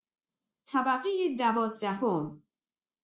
جلوه های صوتی
برچسب: دانلود آهنگ های افکت صوتی اشیاء دانلود آلبوم صدای اعلام طبقات آسانسور از افکت صوتی اشیاء